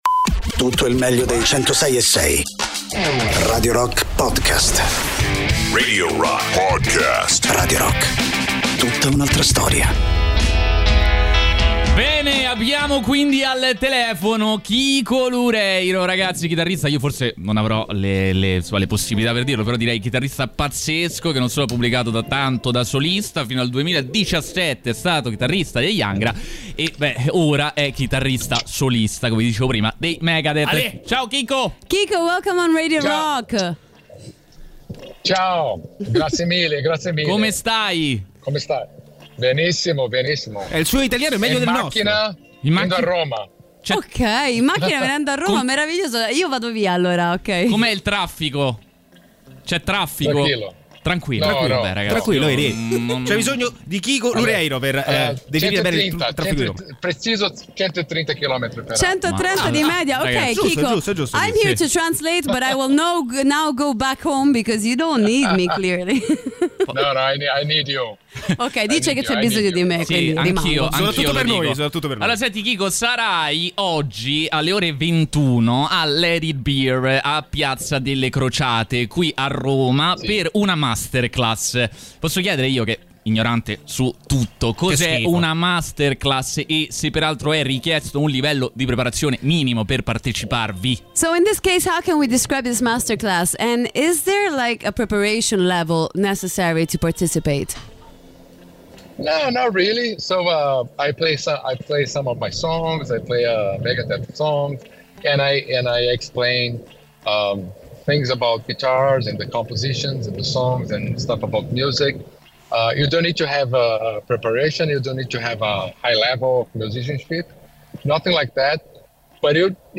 Interviste: Kiko Loureiro (15-01-23)
Kiko Loureiro, ospite telefonico